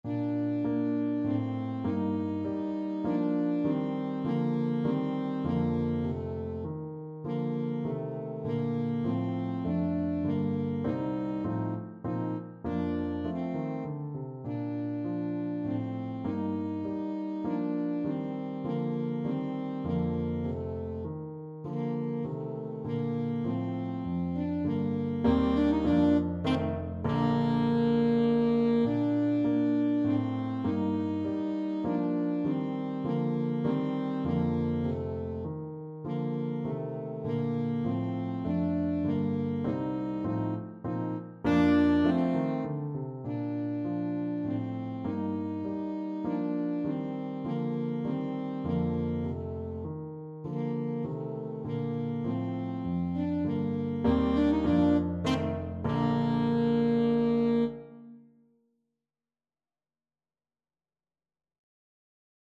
Alto Saxophone
3/4 (View more 3/4 Music)
Moderato
Bb major (Sounding Pitch) G major (Alto Saxophone in Eb) (View more Bb major Music for Saxophone )
Classical (View more Classical Saxophone Music)